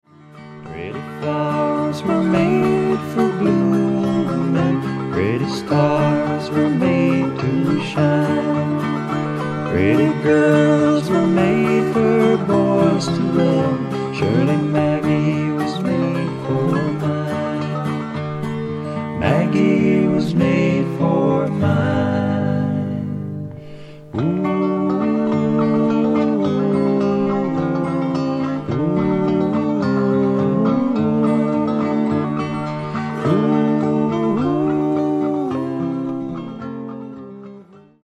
60年代からＮＹ/東海岸を拠点にそれぞれ活動していた、2人のシンガーと3人のインストゥルメンタリストからなる5人組。
ヴォーカル、ギター、ハーモニカ
バンジョー、フィドル、スティール・ギター
ベース、フィドル
ドラムス